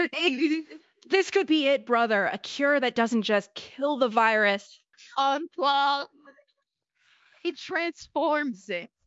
novafarma/assets/audio/voiceover/prologue/prologue_03.wav at 6a01731de0ddb8be01a851d2008a2f67ee82705f